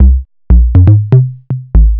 TSNRG2 Bassline 035.wav